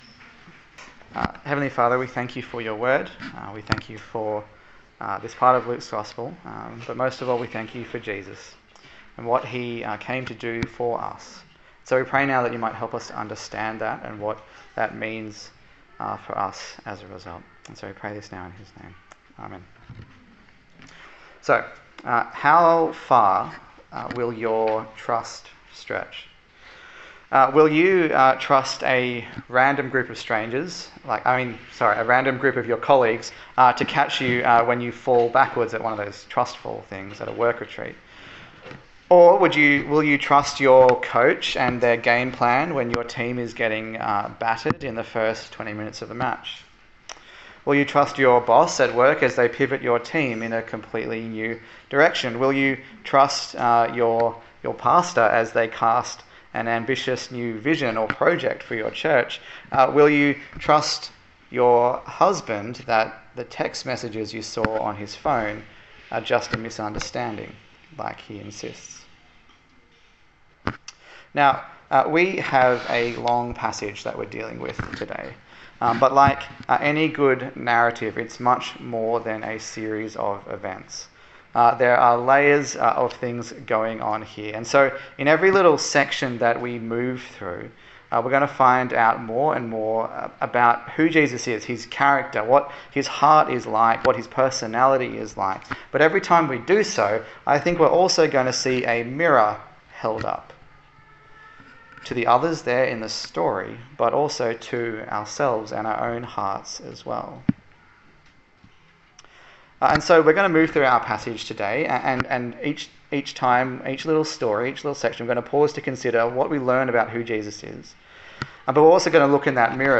A sermon on the Gospel of Luke
Service Type: Sunday Service